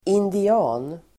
Uttal: [indi'a:n]